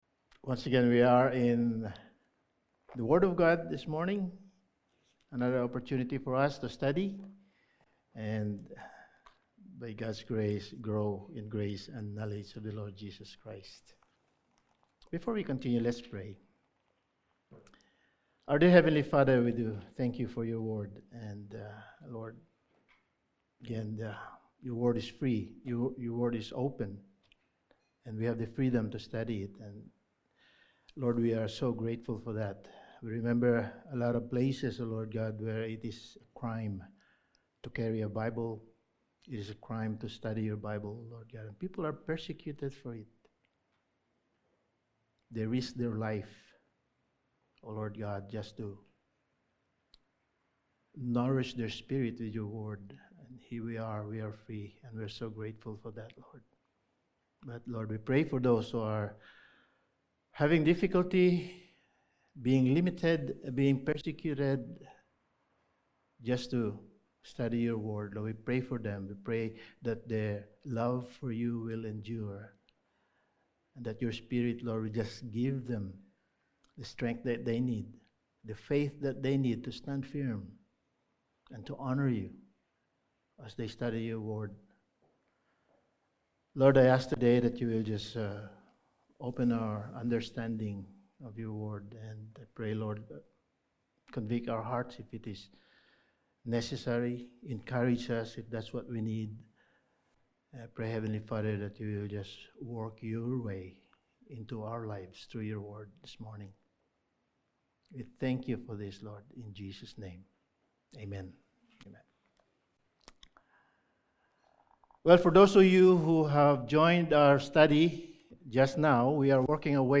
Passage: 1 John 2:7-11 Service Type: Sunday Morning